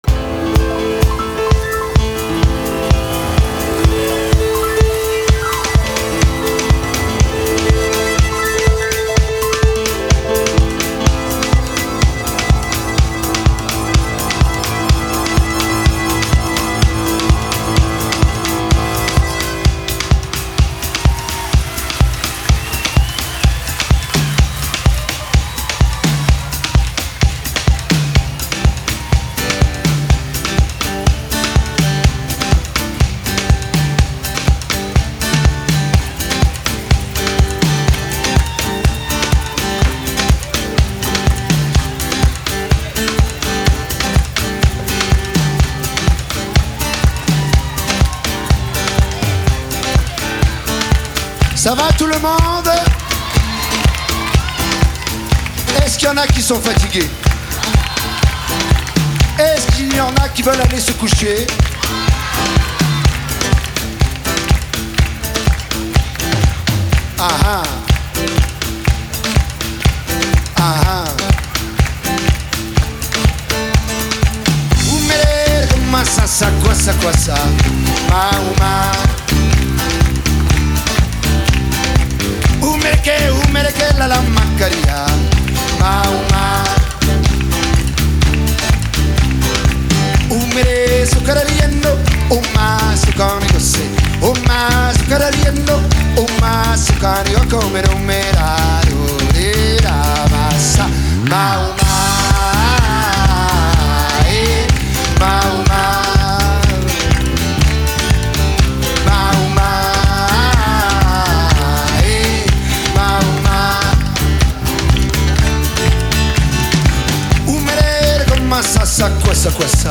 Live show
40 musicians & choir.